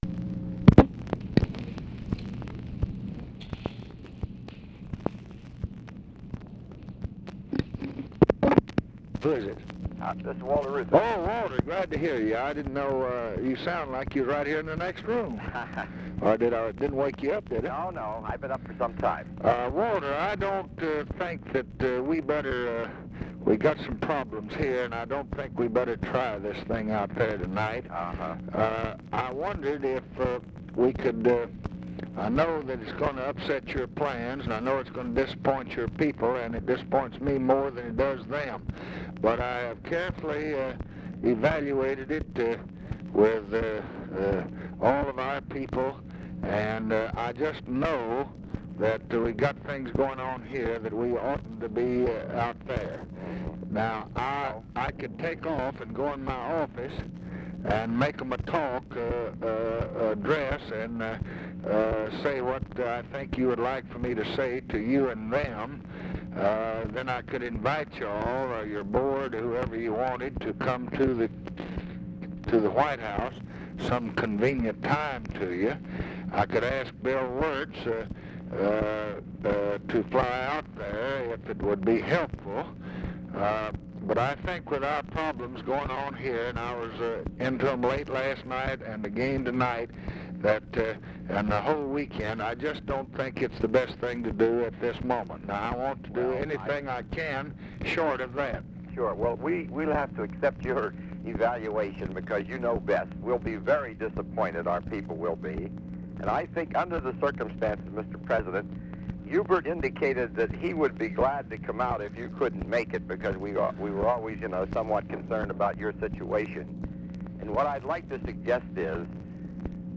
Conversation with WALTER REUTHER, May 20, 1966
Secret White House Tapes